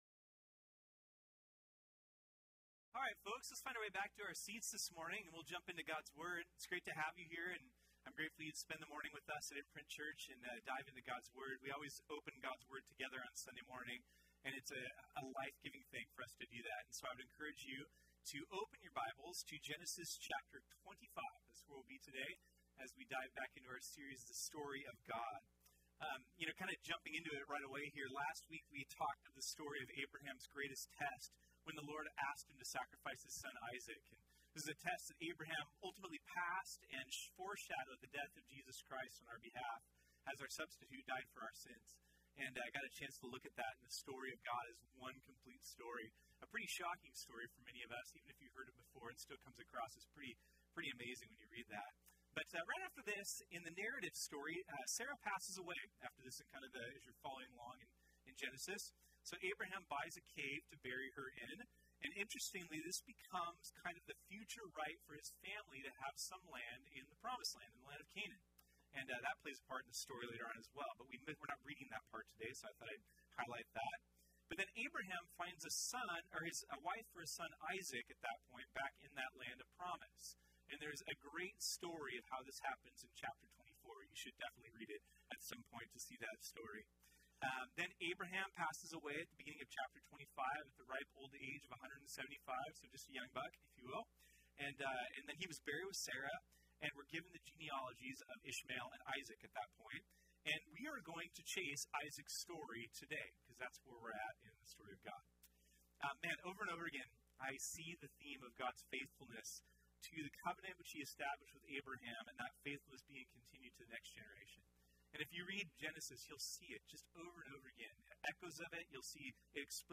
This sermon was originally preached on Sunday, March 17, 2019.